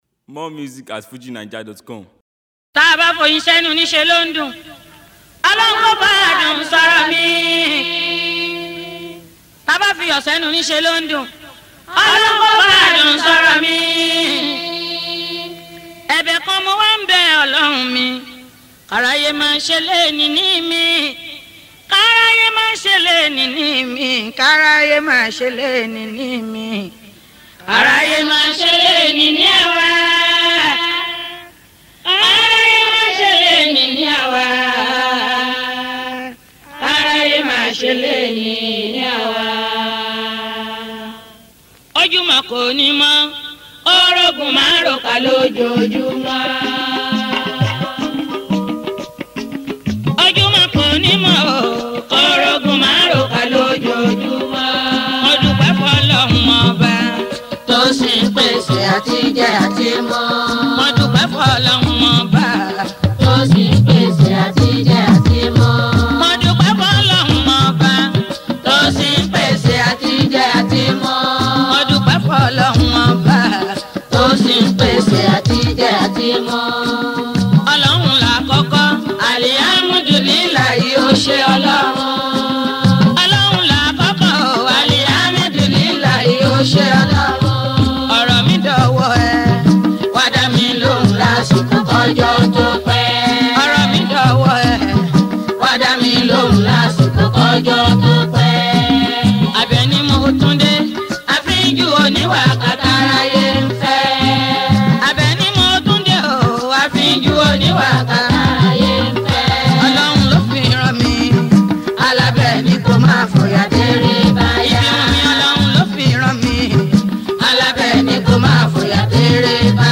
Fuji